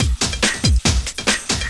04 LOOP07 -L.wav